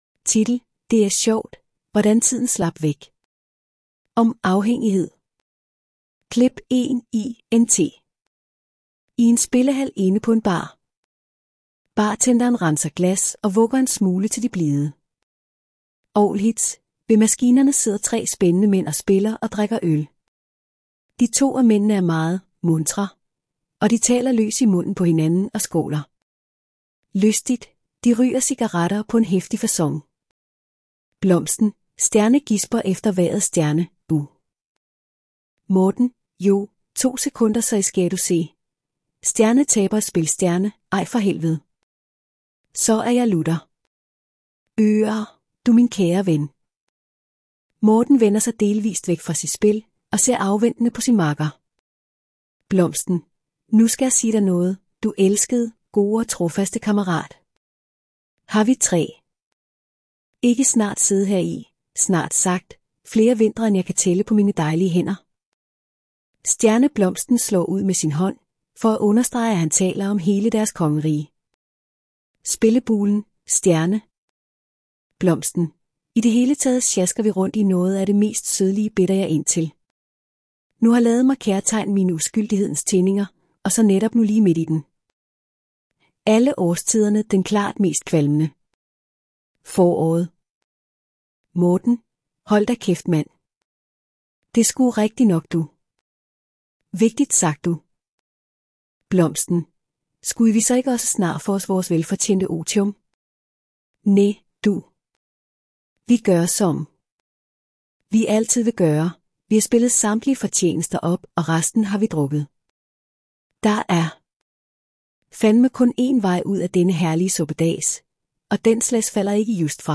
text to speech movie script